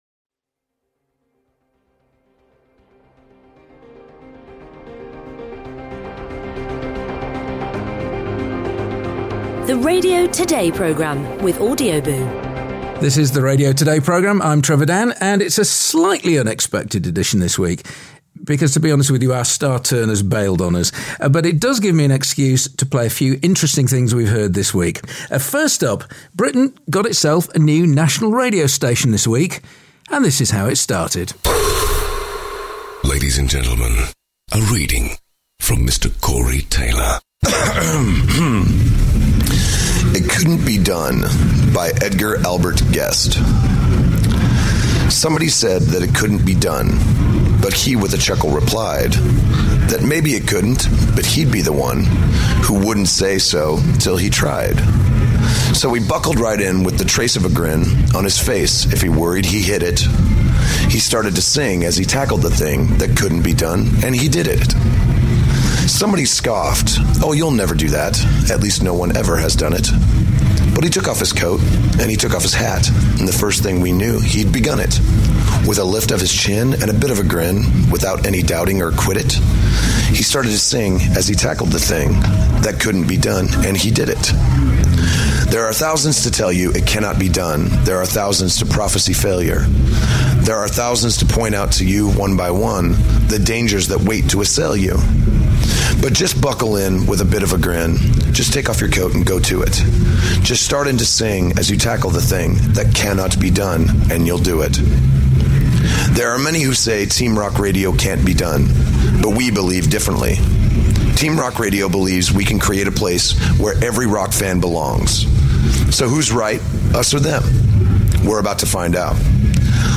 Trevor Dann takes the opportunity to share some of the best bits of audio from the past week - and what a week it's been! Britain got itself a brand new national radio station at 2 Minutes to Midnight on Sunday - Slipknot's Corey Taylor introduces TeamRock Radio.
Voiceover